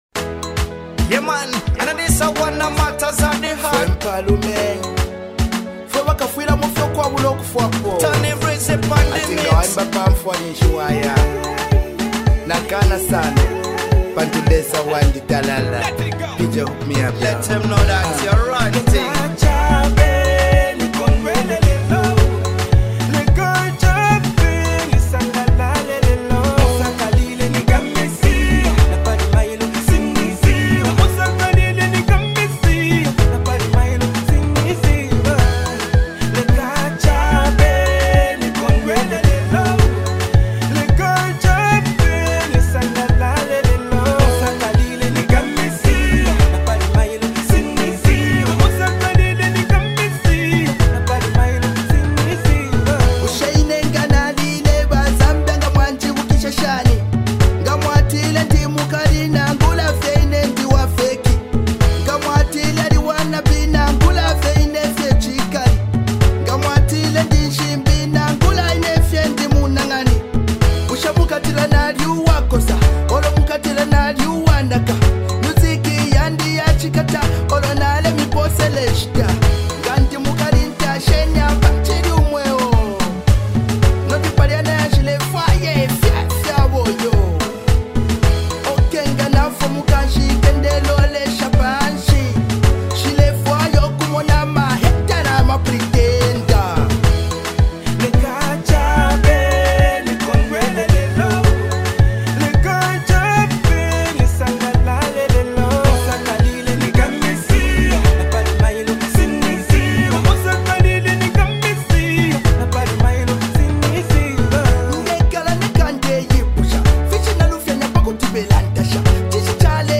The song’s production is vibrant and dynamic